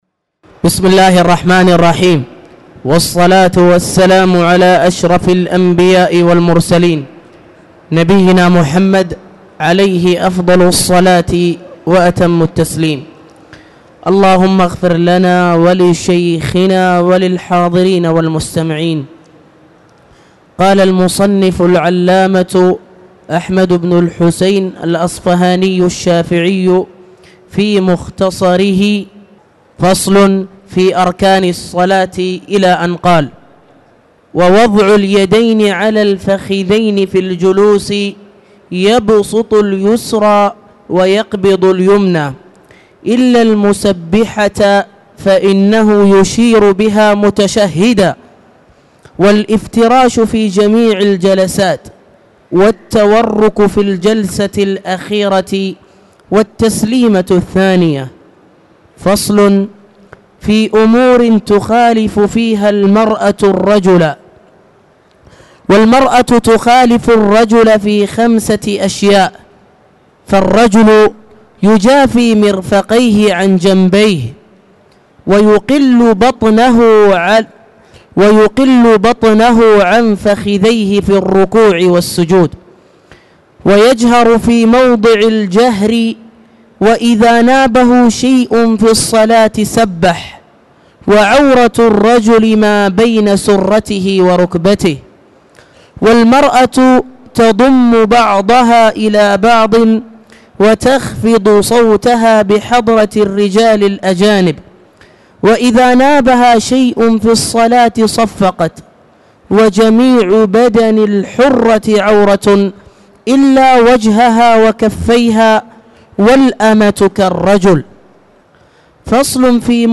تاريخ النشر ١٤ رمضان ١٤٣٧ هـ المكان: المسجد الحرام الشيخ